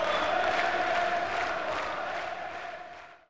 crowdtransition2b.wav